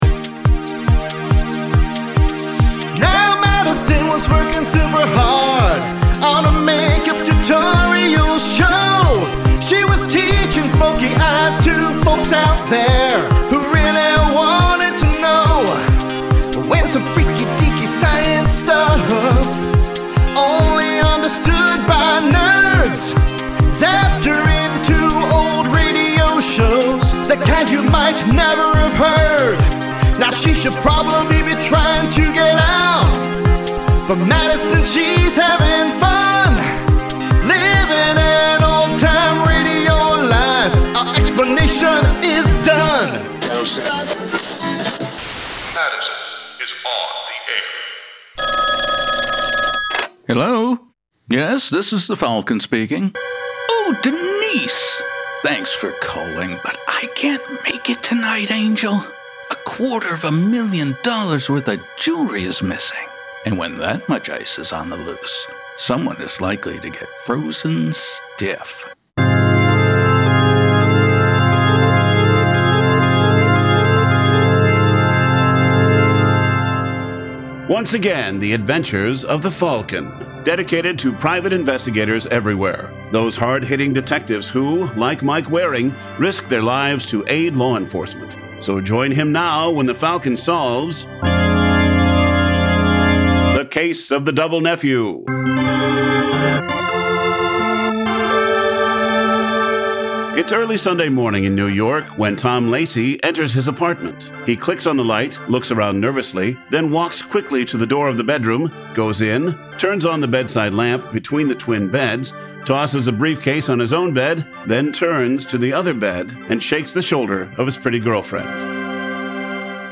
Follow Madison Standish, a modern day influencer, as she gets zapped back into the Golden Age of Radio. Actual OTR scripts adapted!
Audio Drama